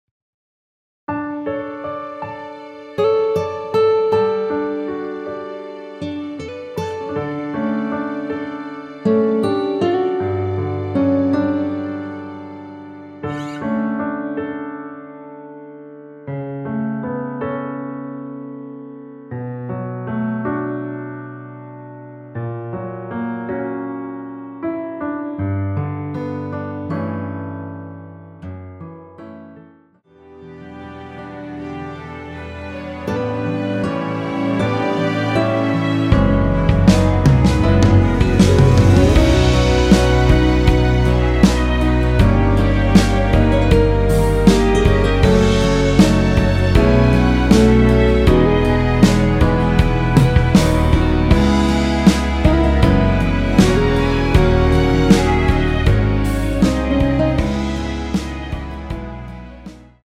원키에서(-2)내린 (1절앞+후렴)으로 진행되는 MR입니다.
◈ 곡명 옆 (-1)은 반음 내림, (+1)은 반음 올림 입니다.
앞부분30초, 뒷부분30초씩 편집해서 올려 드리고 있습니다.
중간에 음이 끈어지고 다시 나오는 이유는